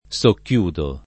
vai all'elenco alfabetico delle voci ingrandisci il carattere 100% rimpicciolisci il carattere stampa invia tramite posta elettronica codividi su Facebook socchiudere [ S okk L2 dere ] v.; socchiudo [ S okk L2 do ] — coniug. come chiudere